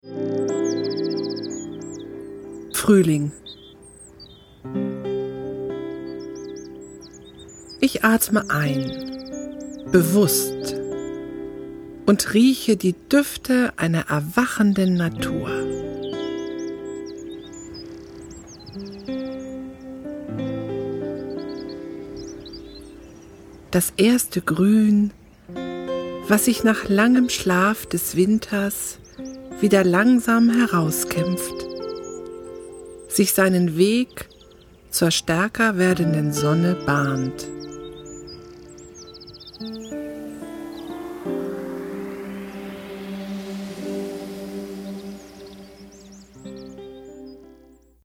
SyncSouls Natur-Meditationen Vol. 1 - geführte Meditation
Eine einstündige Klangreise durch die heimischen 4 Jahreszeiten
Wir laden Sie ein auf eine meditative Reise mit einfühlsamen Texten und wunderschöner Musik durch die vier Jahreszeiten!